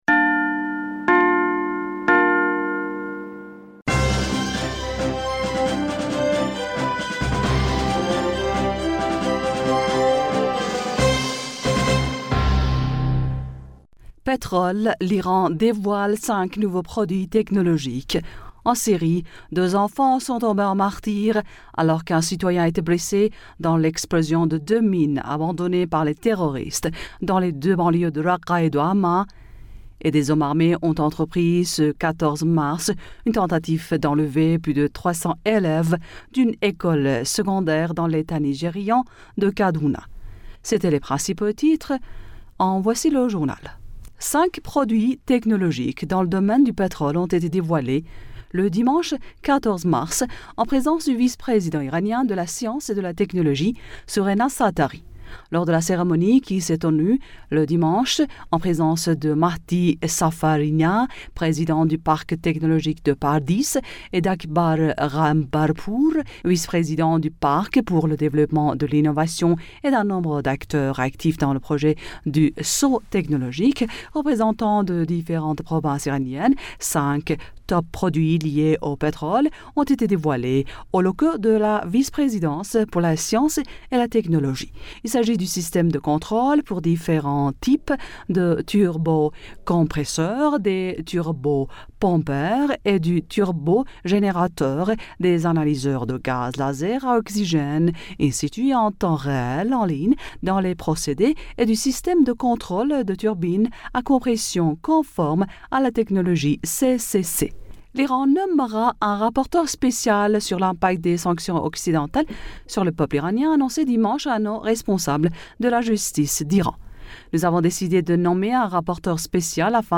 Bulletin d'informationd du 15 Mars 2021